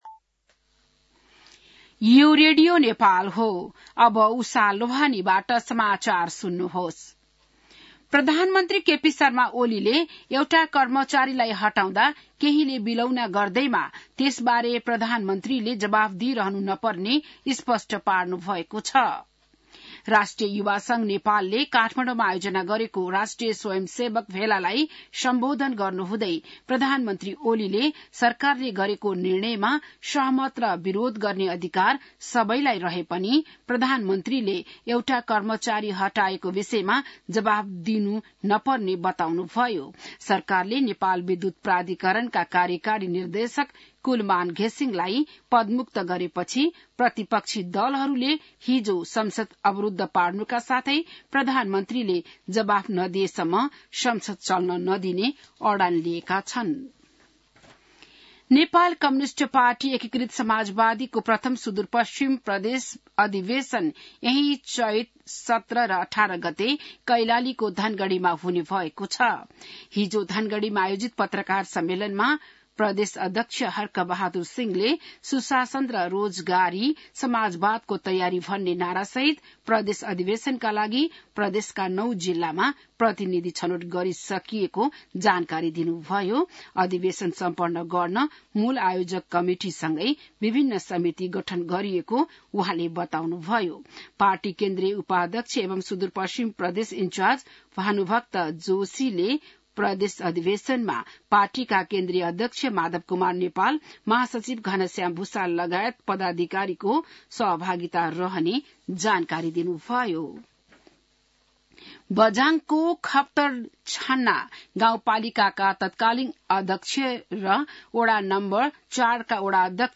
बिहान १० बजेको नेपाली समाचार : १४ चैत , २०८१